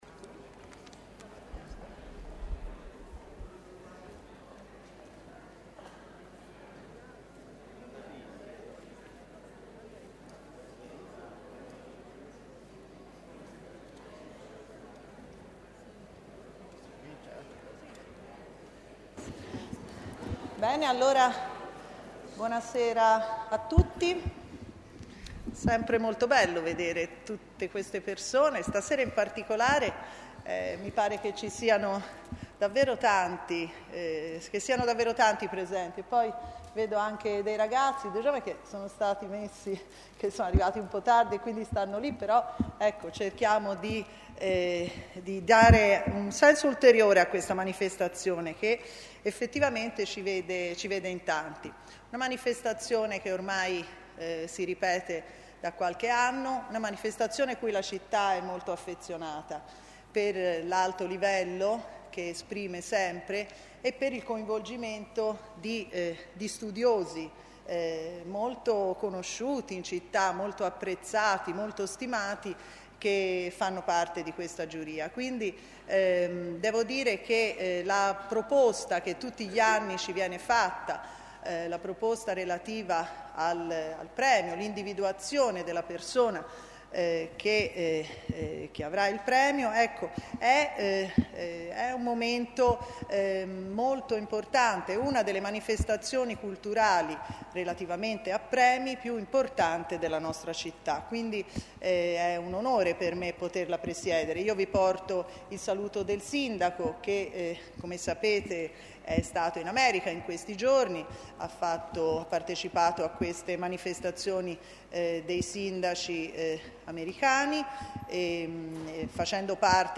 ConferenzaVecchioni.mp3